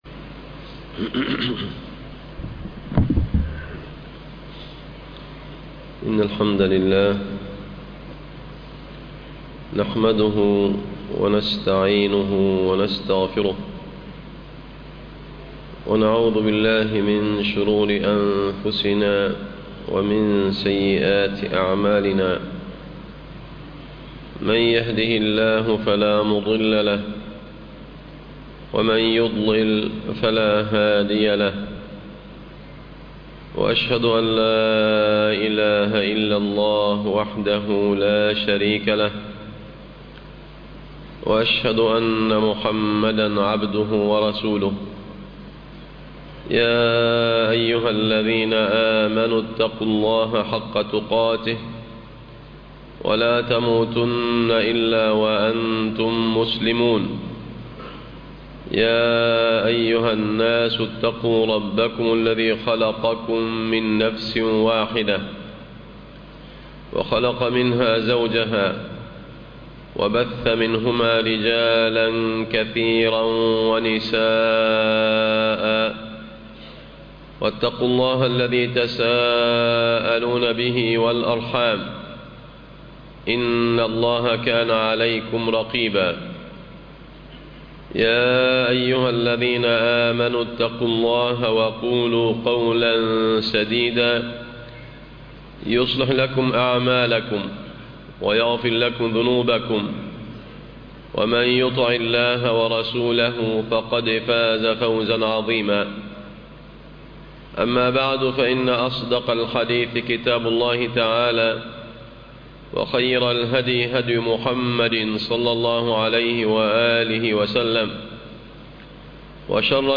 ذم البخل وفضل الصدقة على الأهل - خطب الجمعة